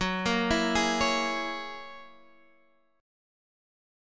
F#9 chord